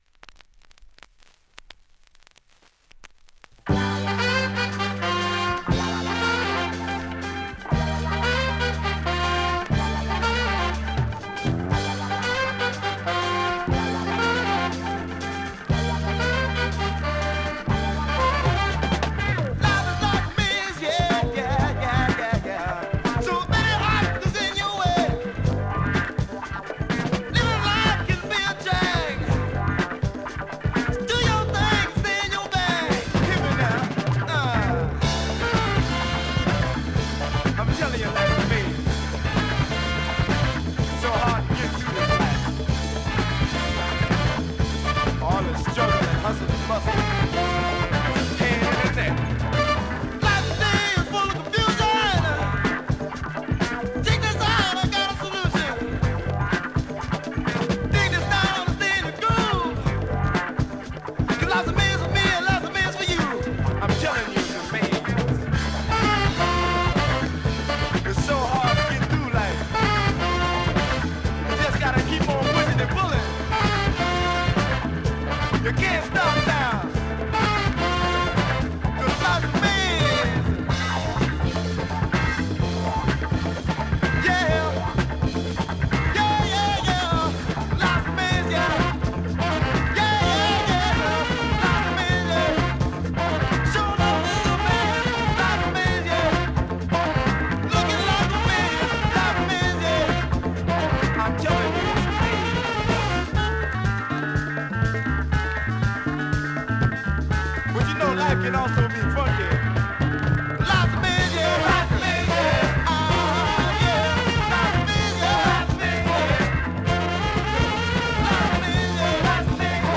USA盤 オリジナル 7"Single 45 RPM現物の試聴（両面すべて録音時間）できます。